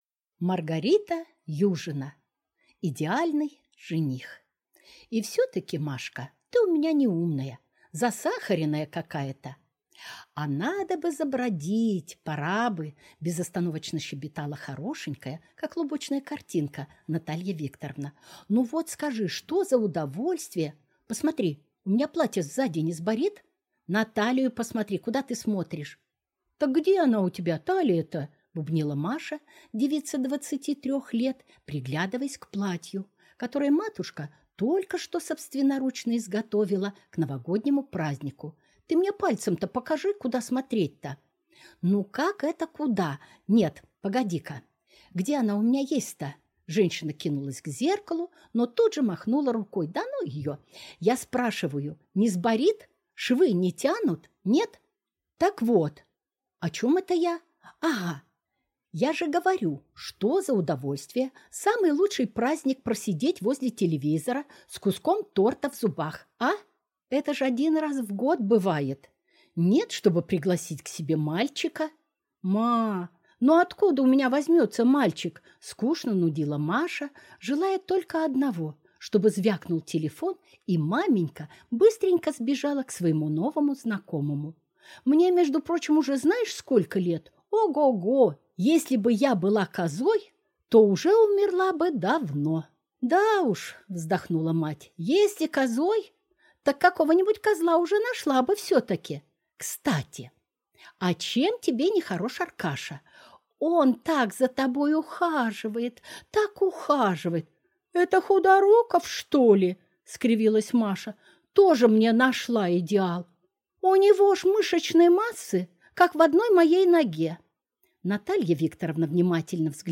Аудиокнига Идеальный жених | Библиотека аудиокниг